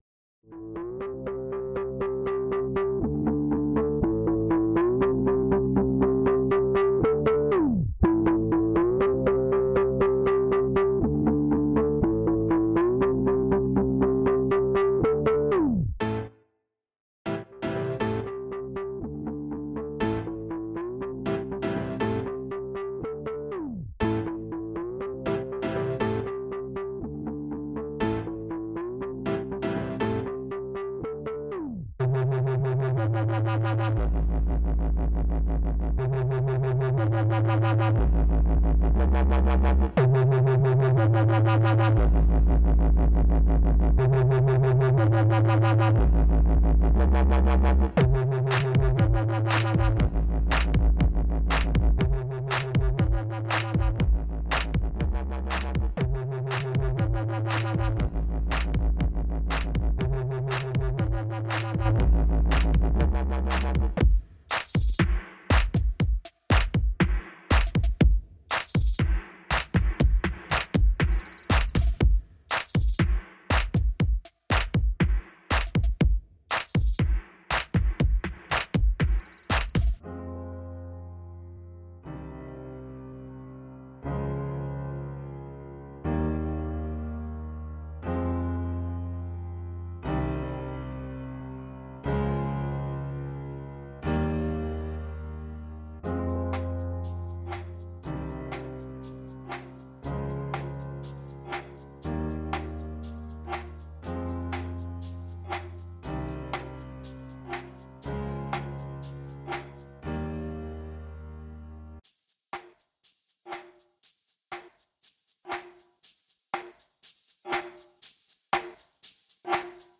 • Жанр: Электронная
У композиции нет текста